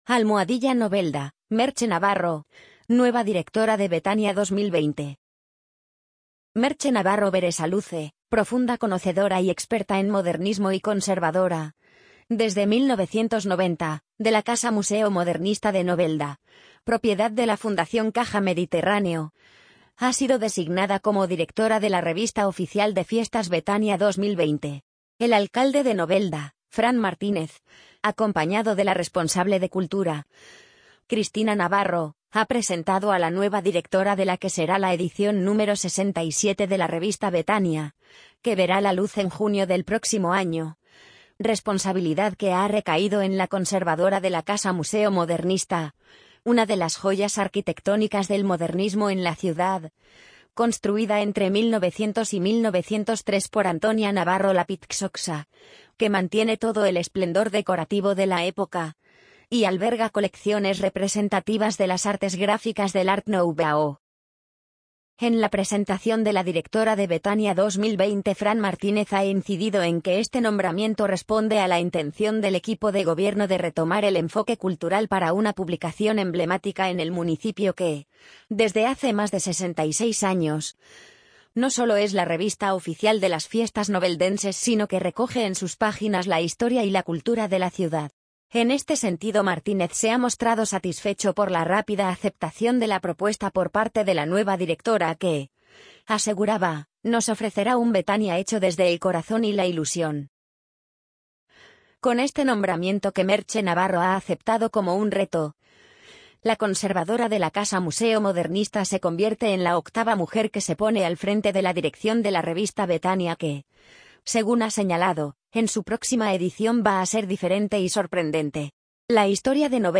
amazon_polly_34465.mp3